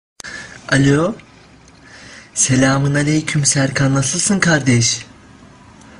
Play Selamun Aleyküm diyen adam.. soundboard button | Soundboardly
selamun-aleykum-diyen-adam.mp3